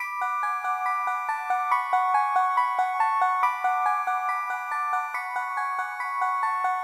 软钢琴140Bpm
Tag: 140 bpm Hip Hop Loops Piano Loops 1.15 MB wav Key : Unknown